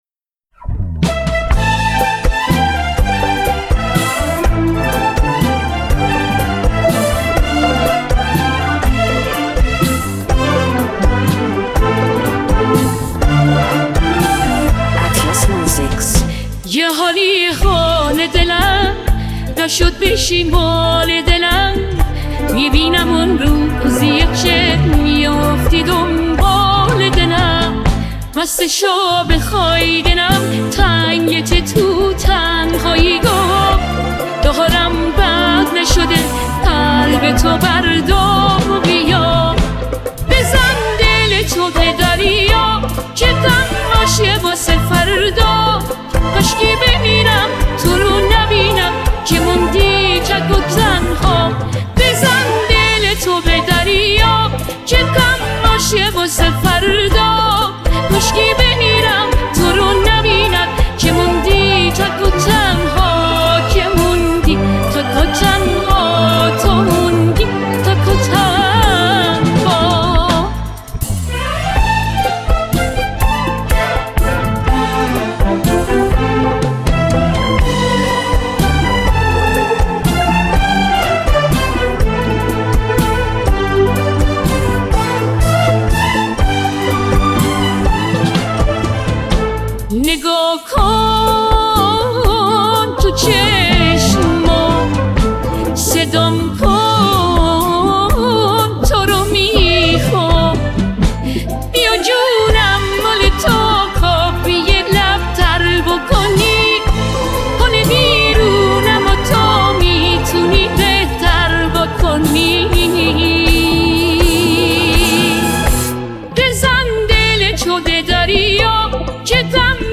اهنگ هوش مصنوعی